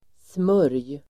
Uttal: [smör:j]